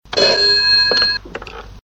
Old Ringing Phone Sound WITH ECHO.mp3
old_ringing_phone_sound_with_echo_fh8.ogg